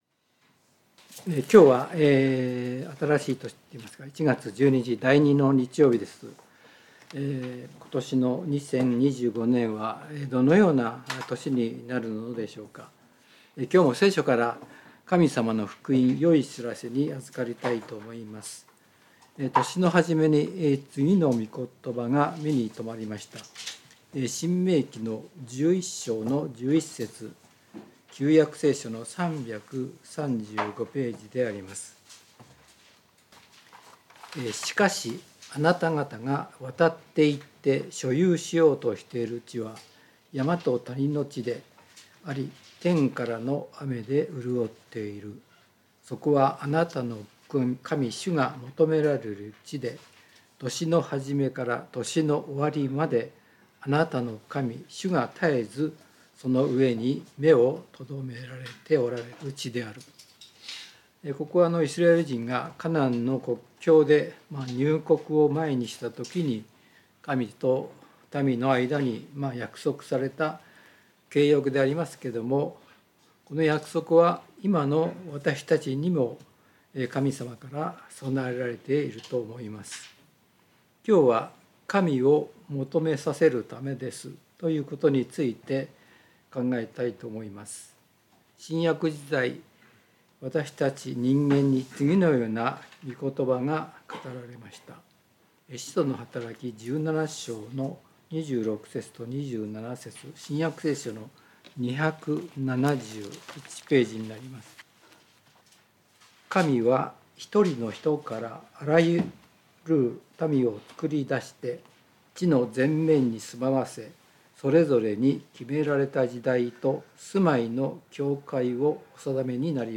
聖書メッセージ No.250